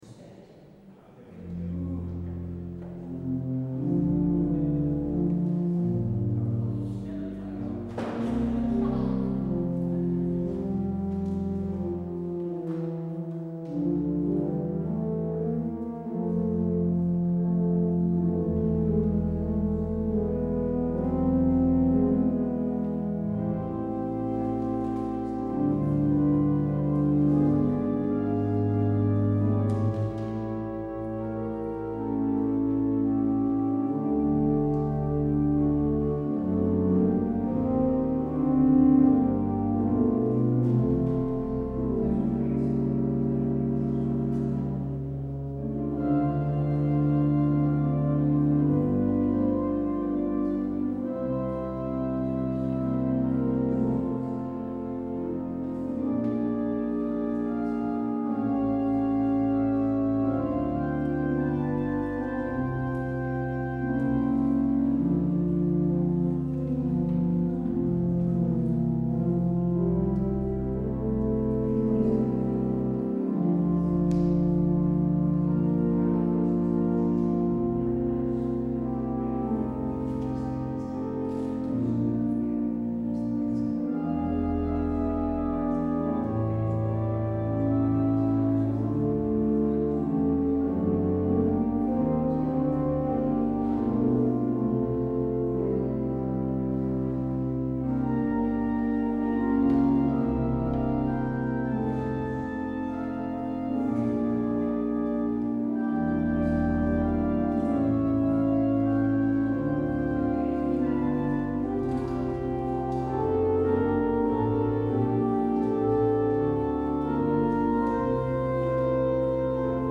Het openingslied is: Lied 280: 1, 2, 3 en 4.